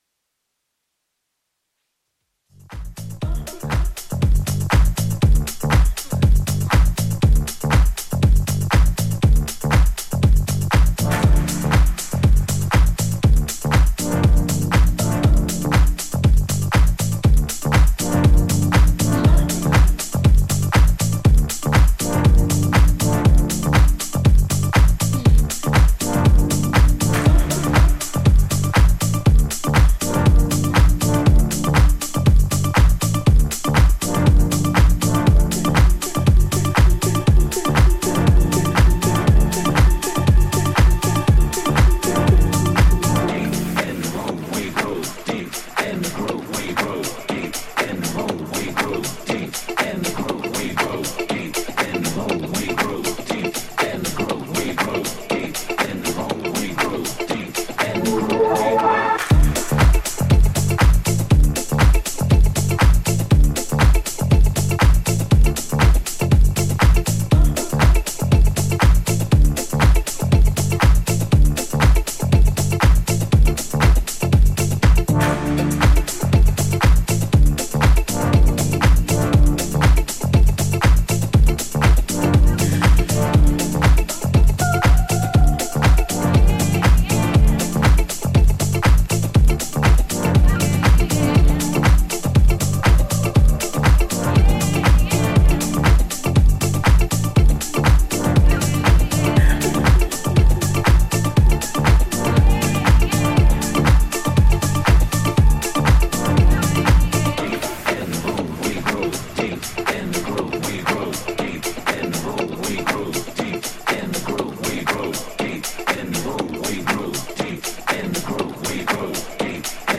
ジャンル(スタイル) HOUSE / SOULFUL HOUSE